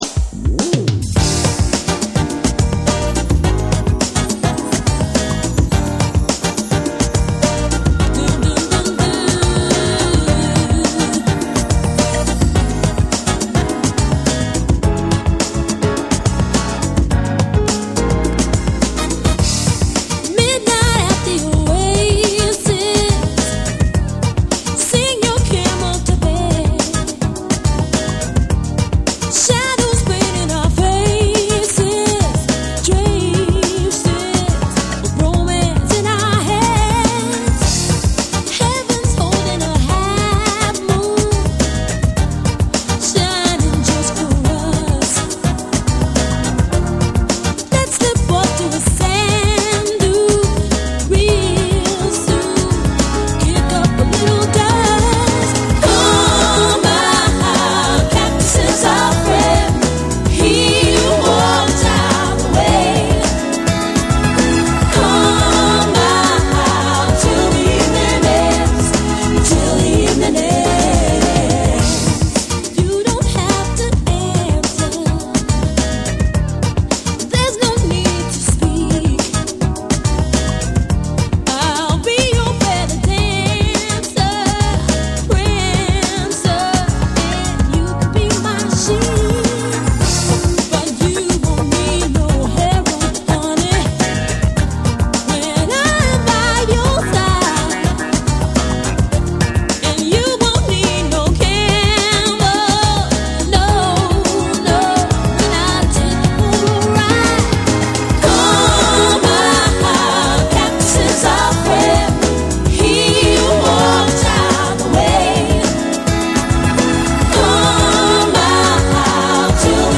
オリジナルのメロウな魅力を軸に、ハウス〜ブレイクビーツ感覚まで行き交う、フロア対応力の高い一枚。
TECHNO & HOUSE / SOUL & FUNK & JAZZ & etc